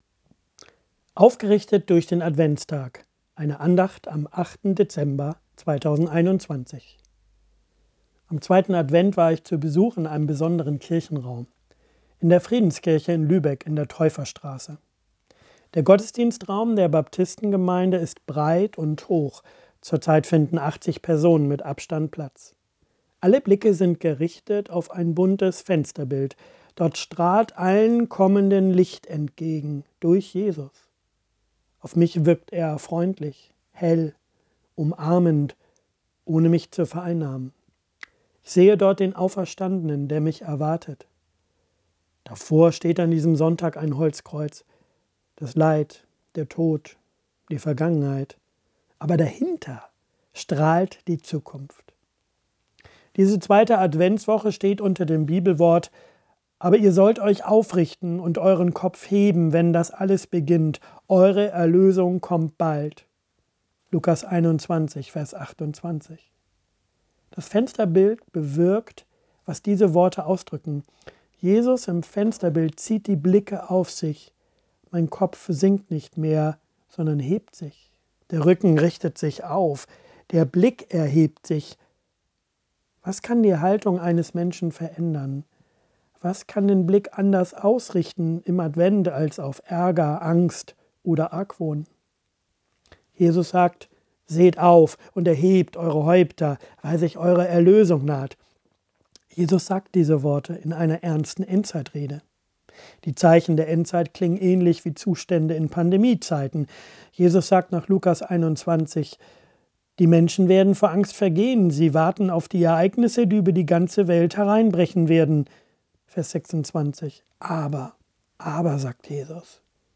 Aufgerichtet durch den Tag im Advent – Kurzandacht